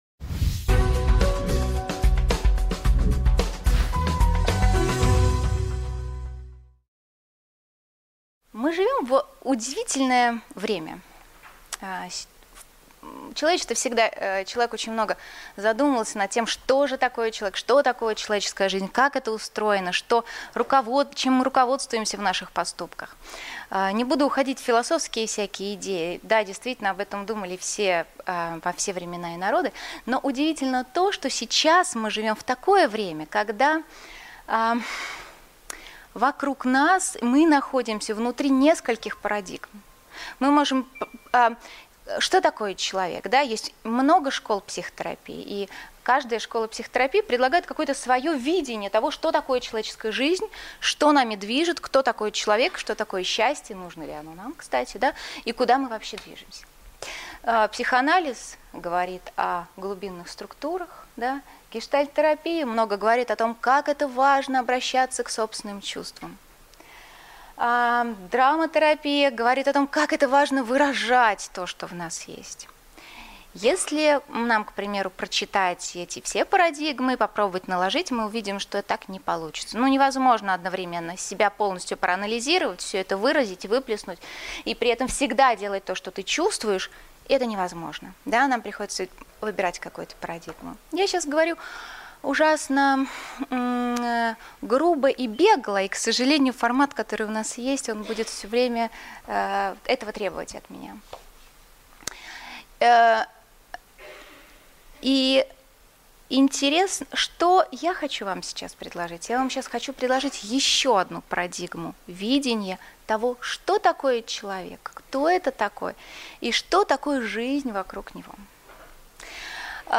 Аудиокнига Переписать историю жизни. Возможно ли это?